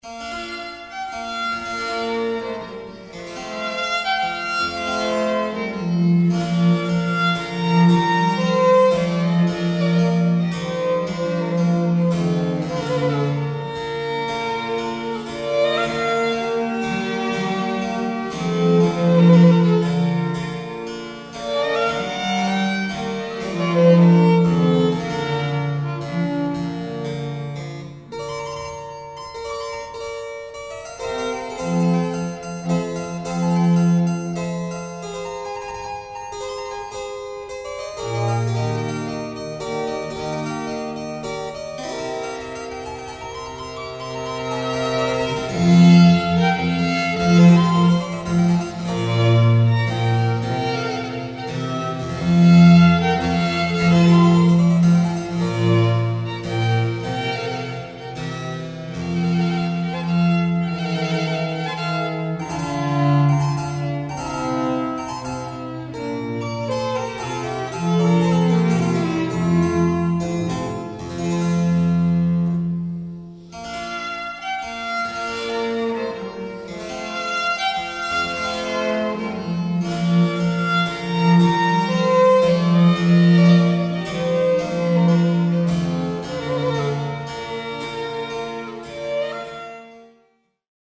Quartet in C major op. 21 n. 6
violin